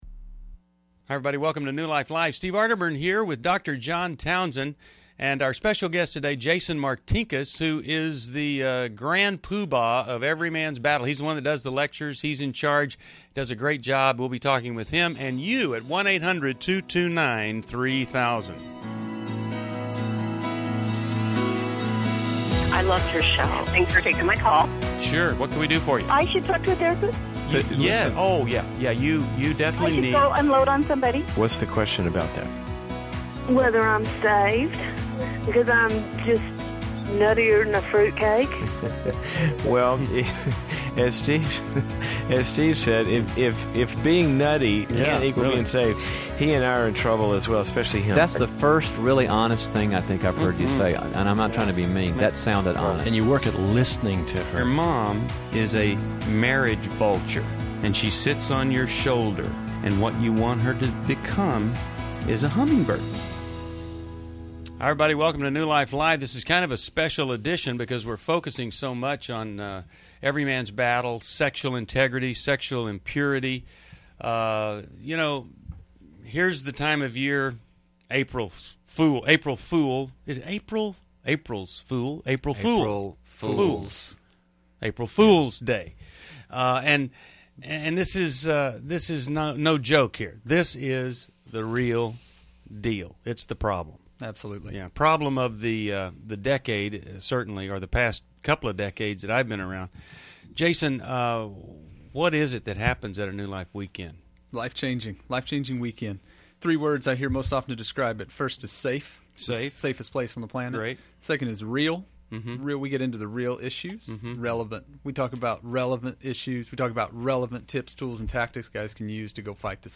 Join New Life Live: April 1, 2011, as experts tackle sexual integrity, parenting challenges, and healing from infidelity through real-life caller stories.